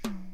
Southside Percussion (20).wav